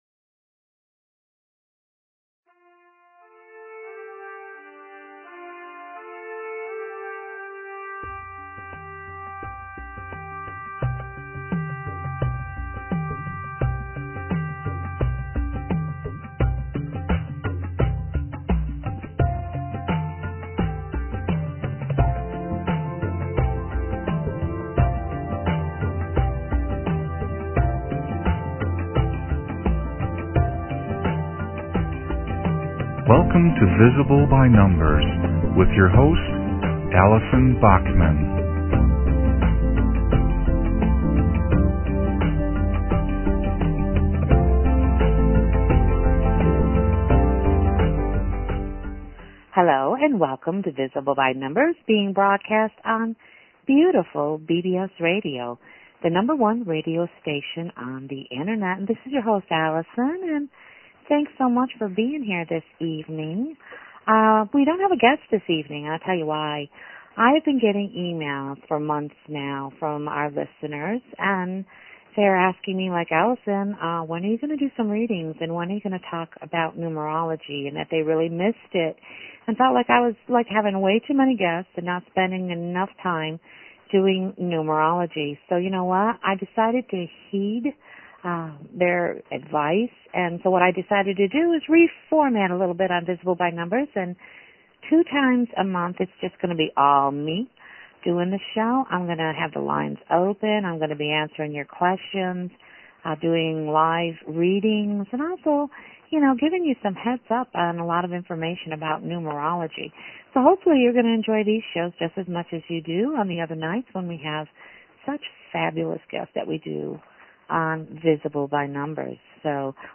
Talk Show Episode, Audio Podcast, Visible_By_Numbers and Courtesy of BBS Radio on , show guests , about , categorized as
Numerology Discussion and Readings